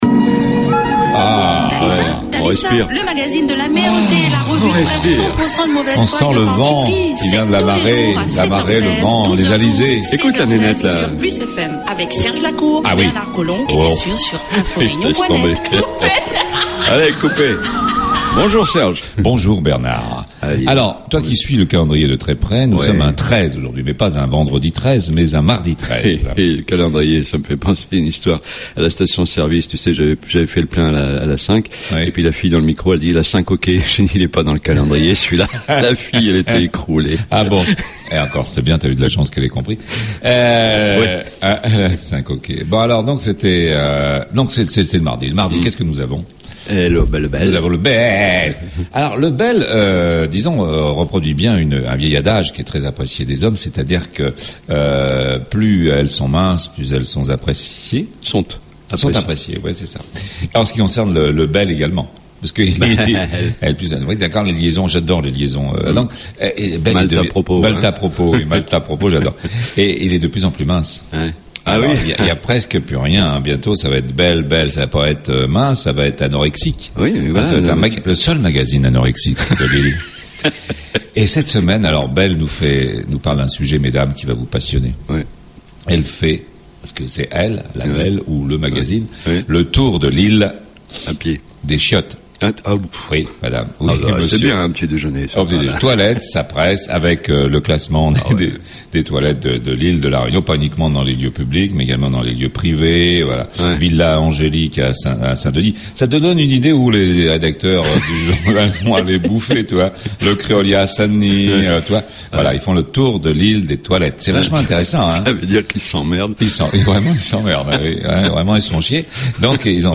La Revue de Presse politiquement incorrecte la mieux informée, la plus décalée, la plus drôle, la moins sérieuse et la plus écoutée sur PLUS FM 100.6 dans le Nord, et 90.4 dans l'Ouest...